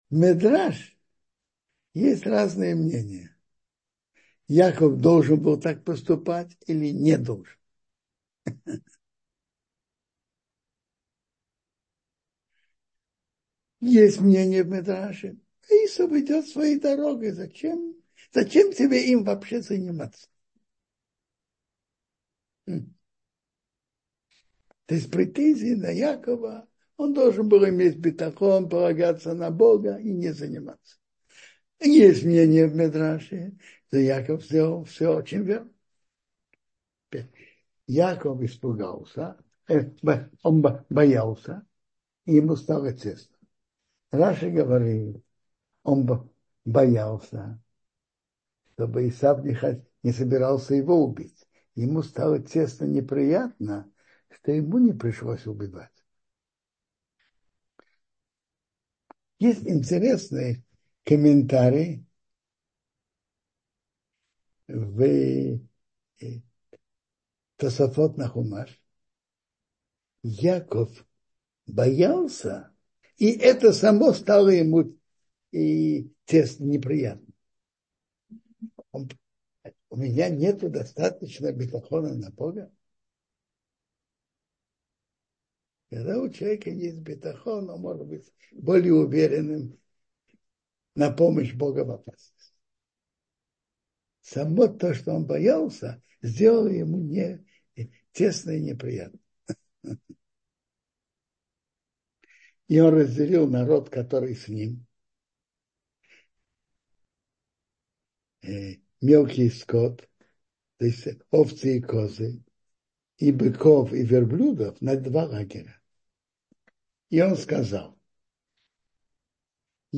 Ваишлах: Подарок, молитва и война — слушать лекции раввинов онлайн | Еврейские аудиоуроки по теме «Недельная глава» на Толдот.ру